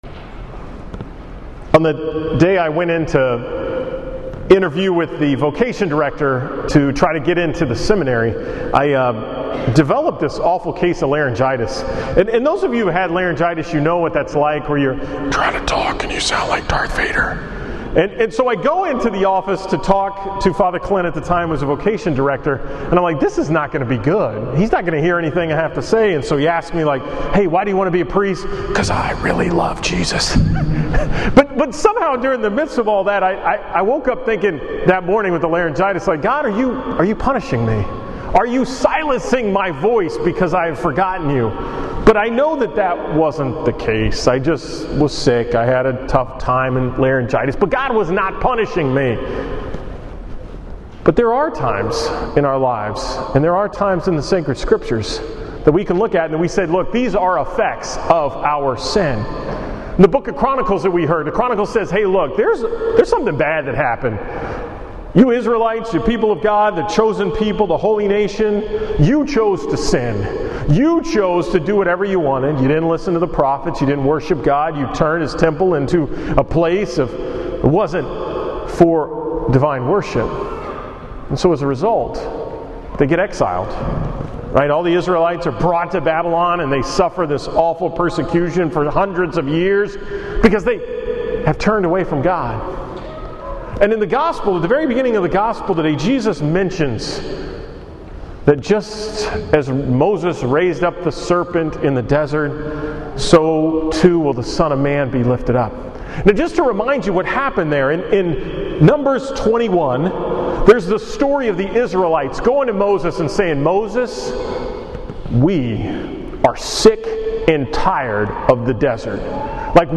From the 5 pm Mass at St. Cyrils on Sunday, March 11, 2018